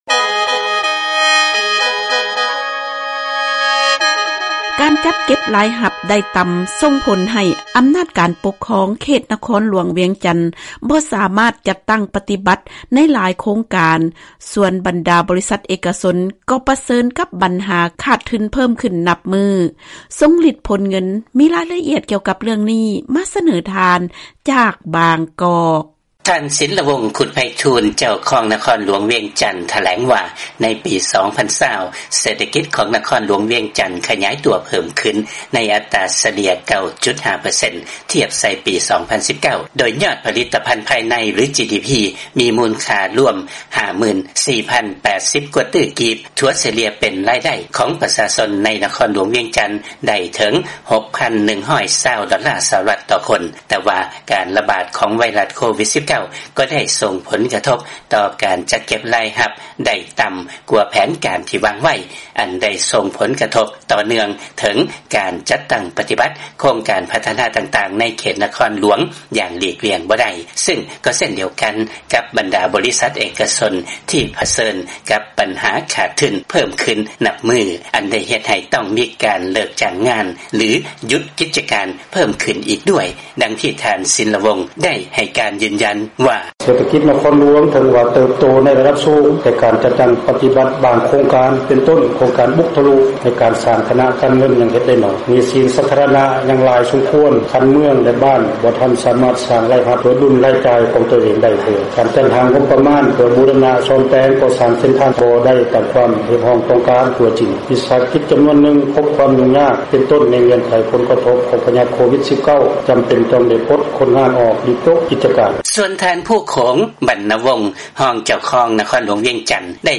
ເຊີນຟັງລາຍງານ ນະຄອນວຽງຈັນ ຈັດເກັບລາຍຮັບໄດ້ຕ່ຳກວ່າເປົ້າໝາຍ ໃນຂະນະທີ່ ບັນດາບໍລິສັດເອກະຊົນ ໄດ້ຮັບຜົນເສຍຫາຍໜັກ